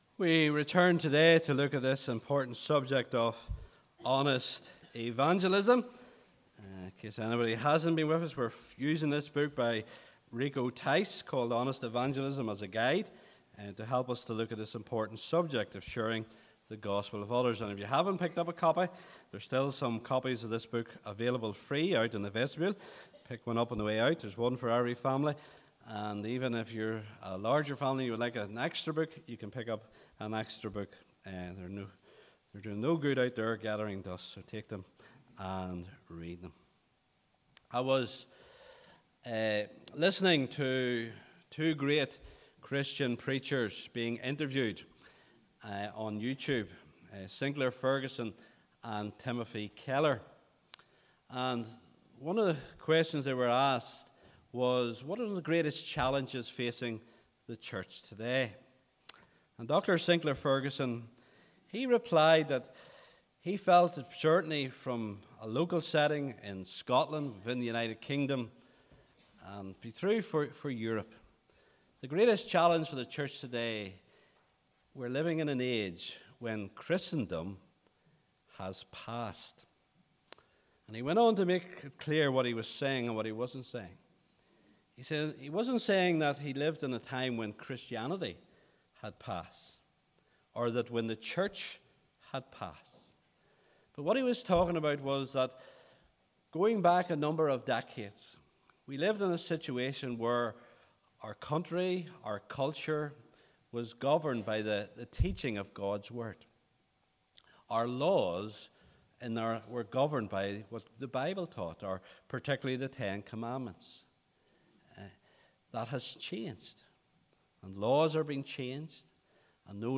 SERMON SERIES Honest Evangelism Part 1: Two Halves of the Story Part 2: Is it Worth it?